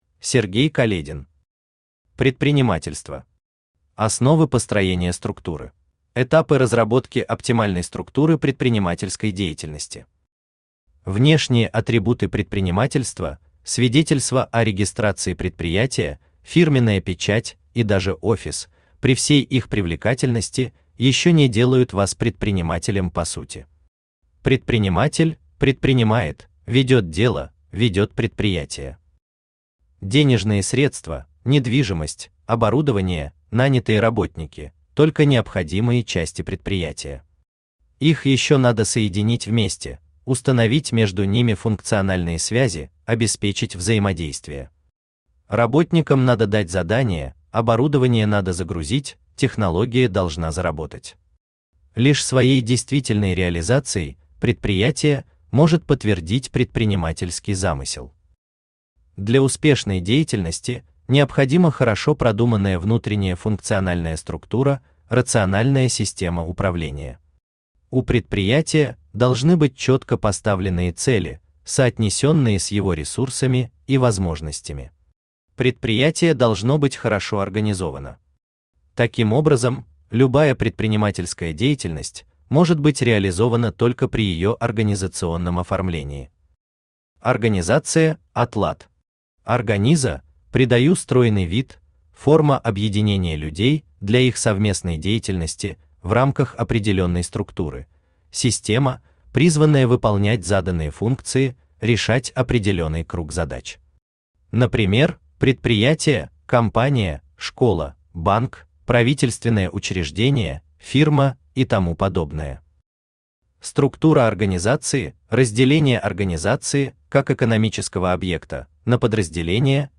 Аудиокнига Предпринимательство. Основы построения структуры | Библиотека аудиокниг
Основы построения структуры Автор Сергей Каледин Читает аудиокнигу Авточтец ЛитРес.